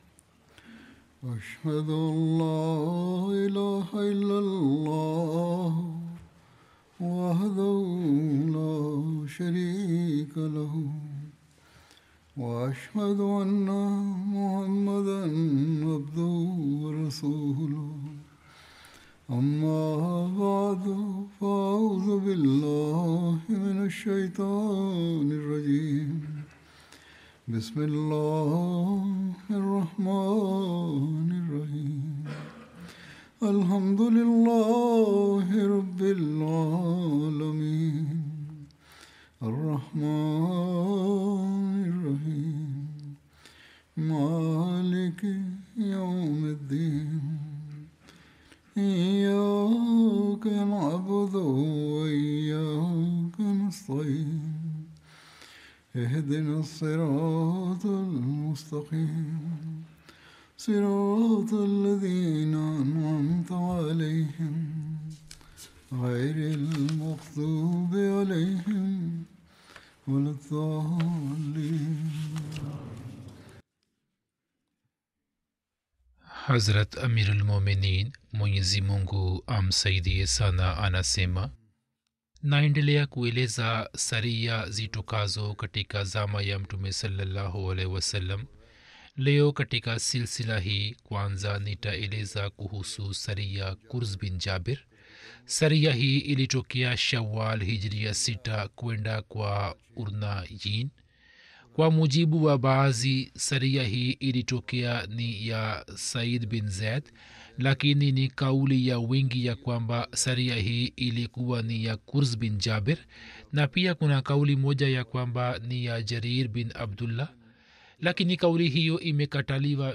Swahili translation of Friday Sermon